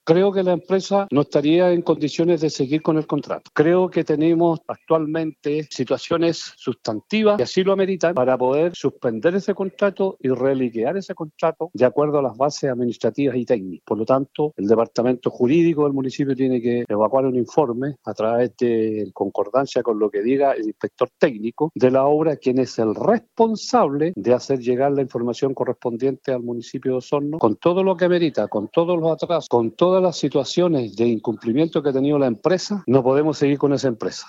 Además, el edil afirmó que el próximo martes en el pleno del Concejo Municipal solicitará un informe de la situación contractual que tiene la empresa a cargo de esta iniciativa.
concejal-osorno.mp3